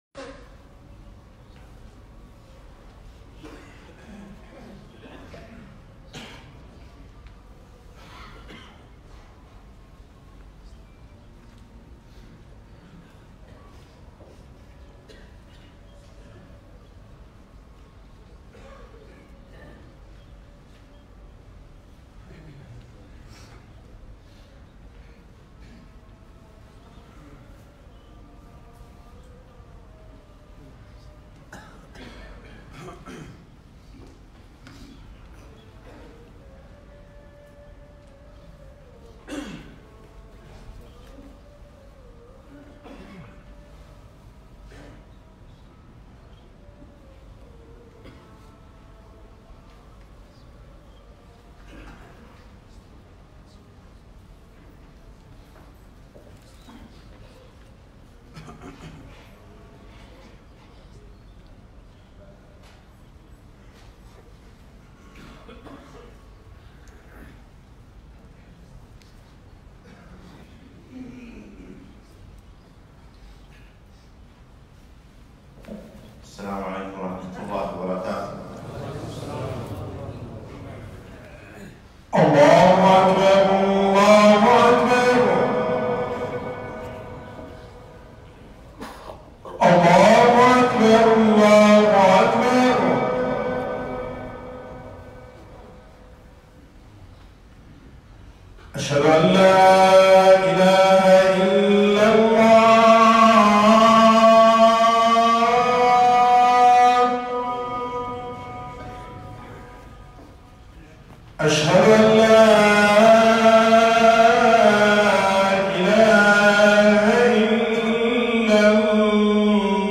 خطبة - وأحبب من شئت فإنك مفارقه